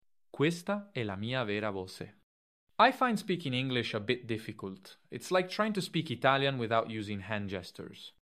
智东西8月7日报道，今天，MiniMax推出新一代语音生成模型Speech 2.5
同一音色在意大利语、英语间的切换：
在不同的语言中切换，Speech 2.5生成的内容依旧可以保留口音特色细节。